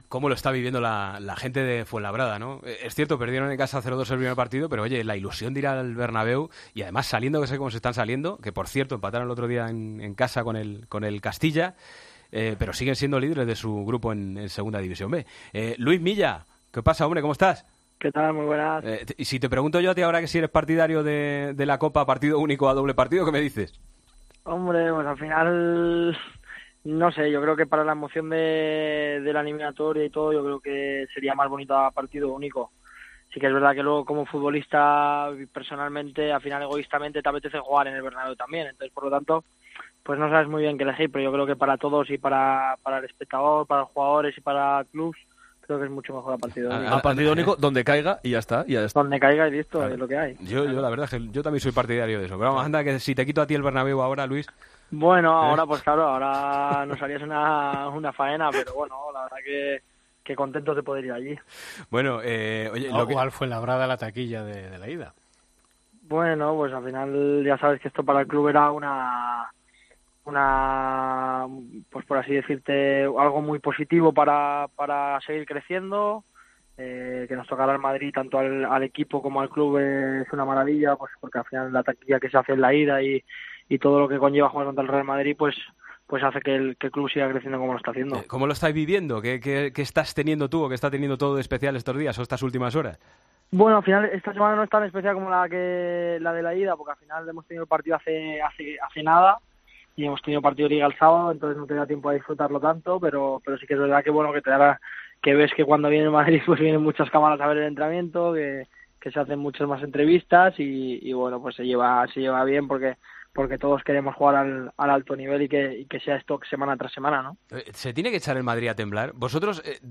Hablamos con el jugador del Fuenlabrada que este martes juega en el Bernabéu la vuelta de los dieciseisavos la Copa del Rey.